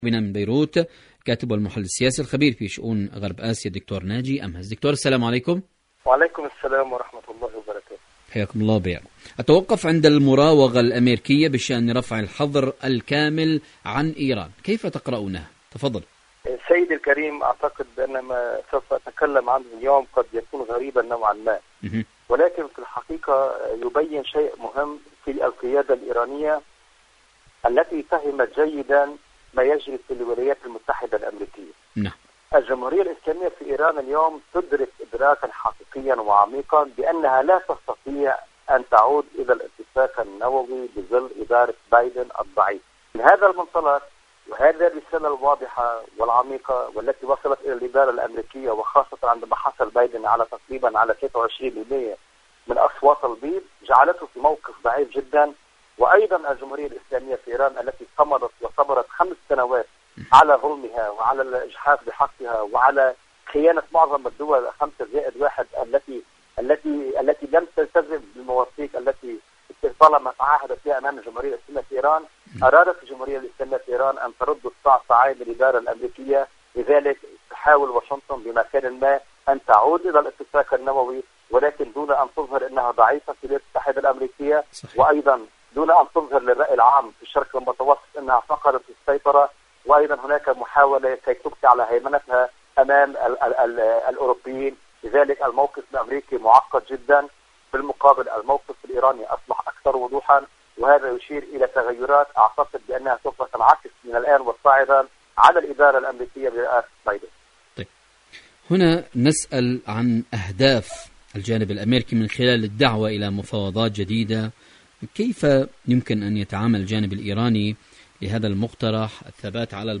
إذاعة طهران-إيران اليوم المشهد السياسي: مقابلة إذاعية